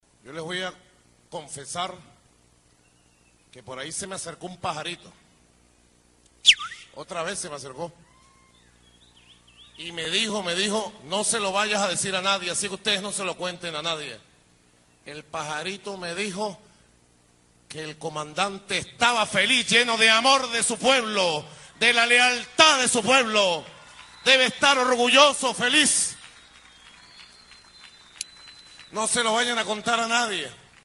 Parte del discurso del presidente Nicolás Maduro sobre este tema